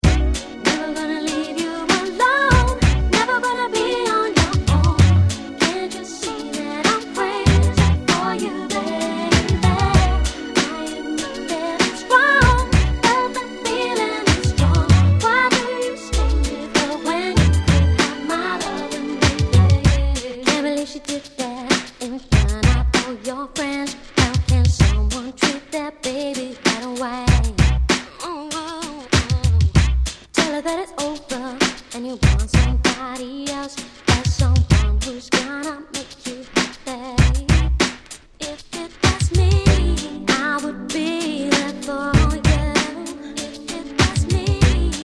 Nice UK R&B♩
めちゃくちゃ爽やか。